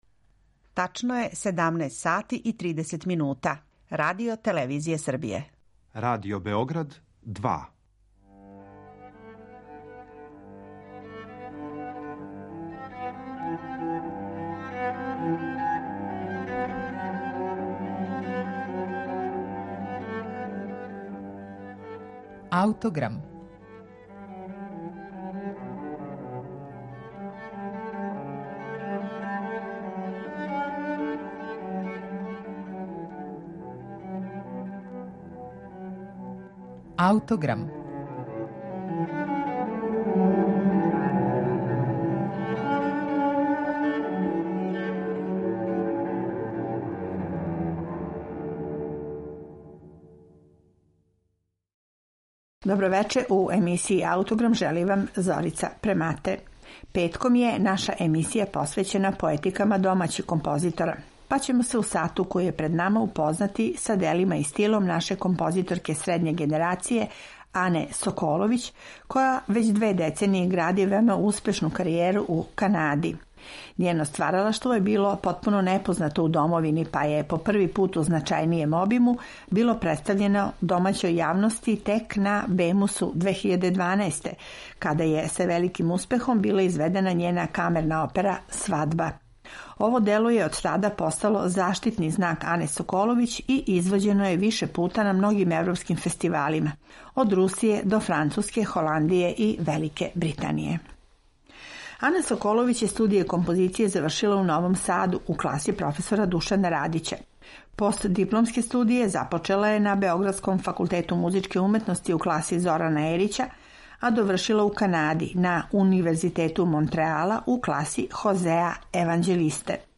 камерне свите